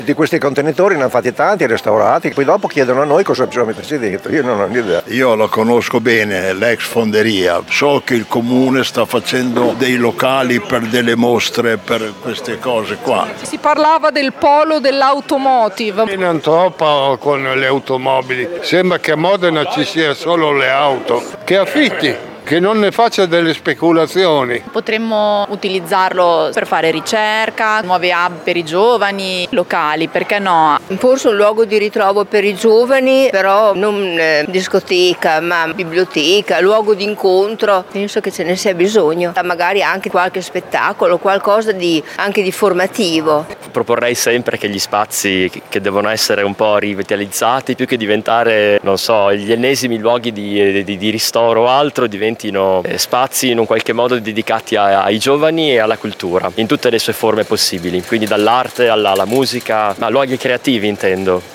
VOX-FONDERIE.mp3